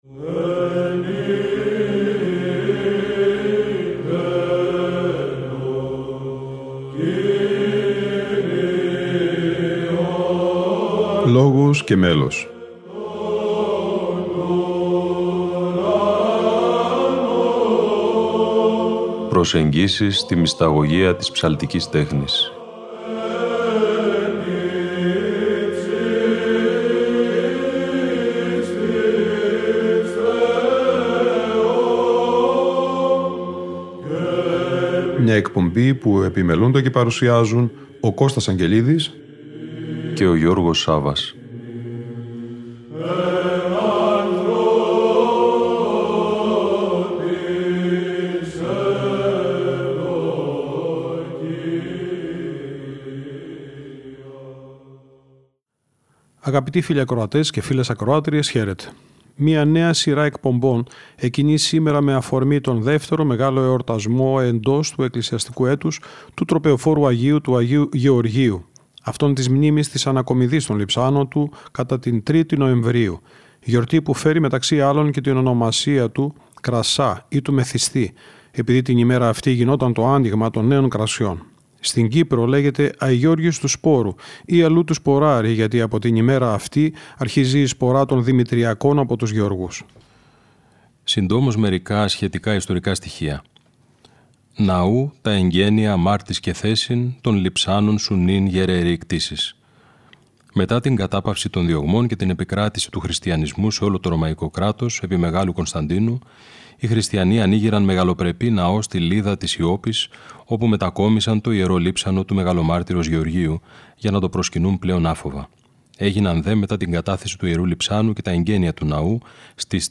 Αγρυπνία Ανακομιδής Λειψάνων Αγίου Γεωργίου - Ροτόντα 2001 (Α΄)